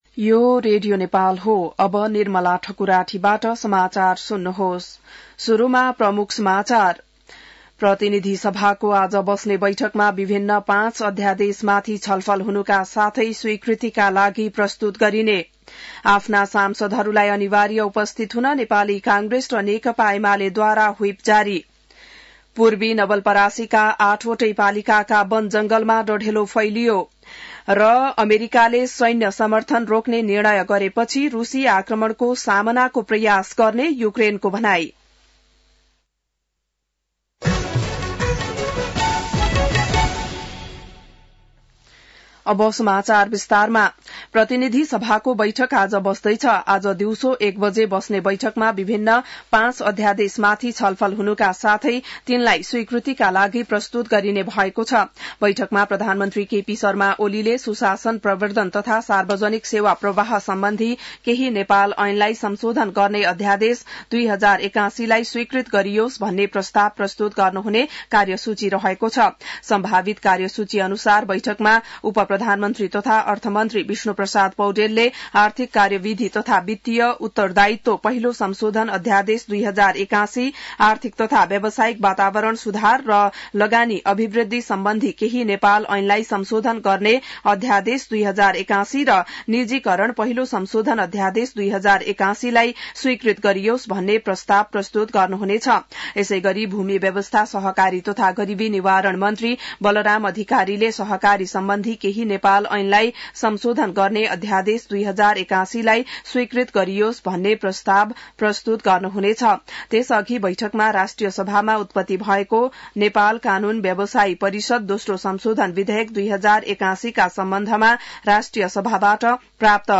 बिहान ९ बजेको नेपाली समाचार : २२ फागुन , २०८१